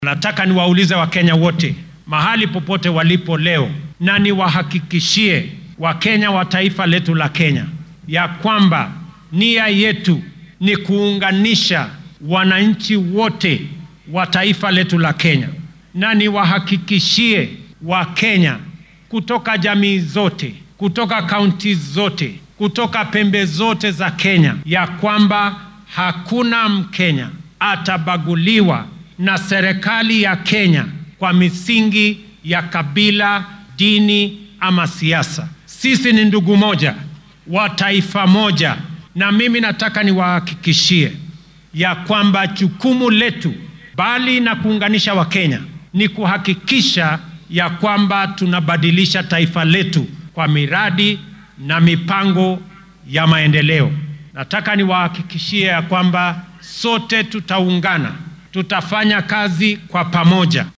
Madaxweynaha dalka William Ruto ayaa khudbadiisa maanta ee xuska maalinta qaran ee halyeeyada wadanka ku sheegay in dowladda dhexe ay ka go’an tahay inay xoojiso midnimada shacabka.